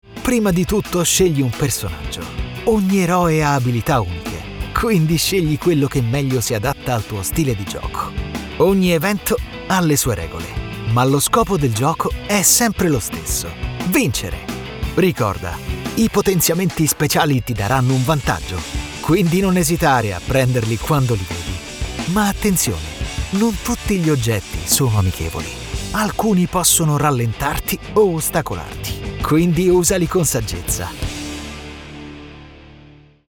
Male
Bright, Engaging, Friendly, Versatile, Authoritative, Character
Discovery Documentary.mp3
Microphone: Neumann TLM 103, Universal Audio Sphere Dlx